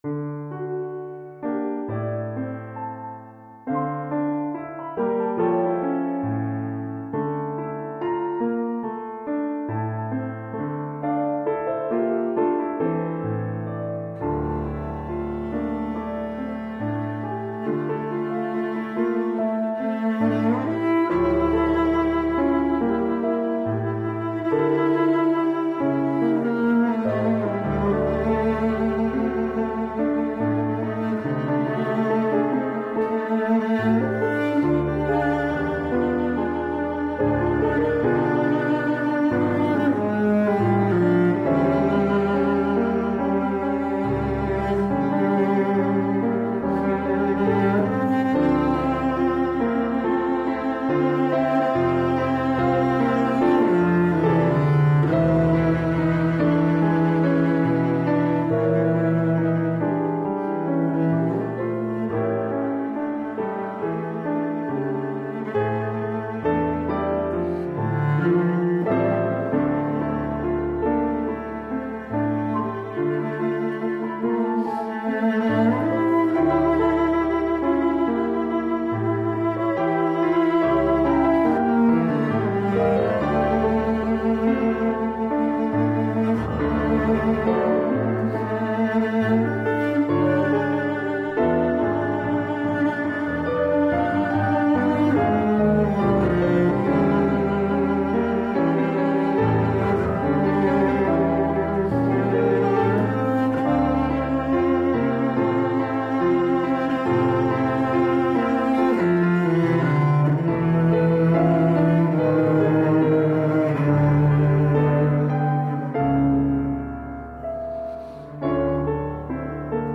für Violoncello und Pianoduo